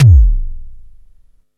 SIMMONS SDS7 3.wav